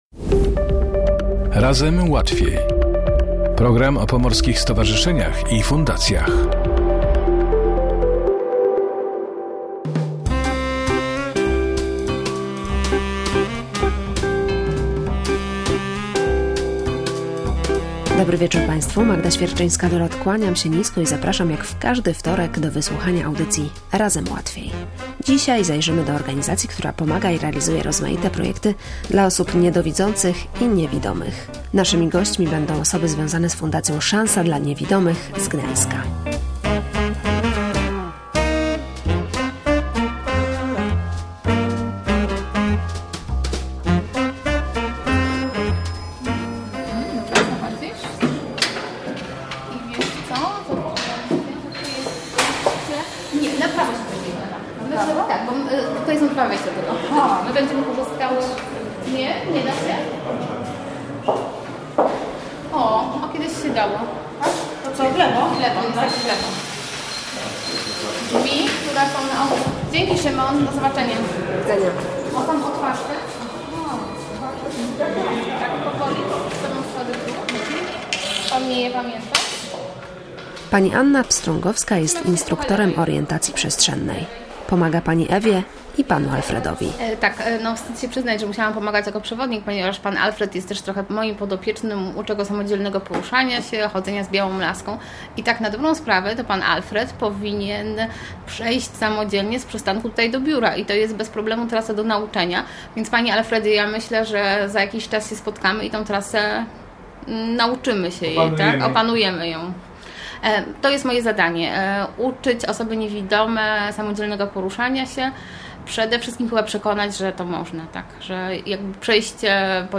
Gośćmi audycji „Razem Łatwiej” były osoby związane z Fundacją „Szansa dla Niewidomych”.